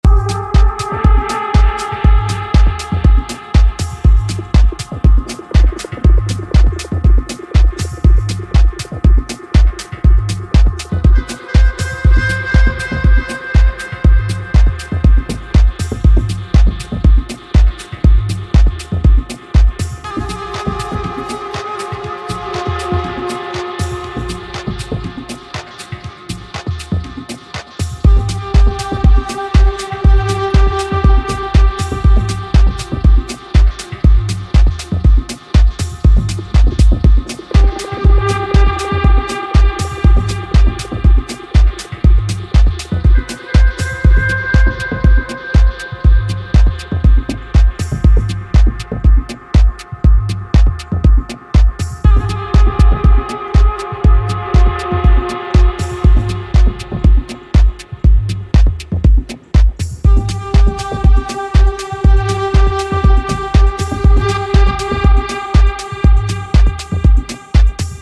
a dark and chugging track.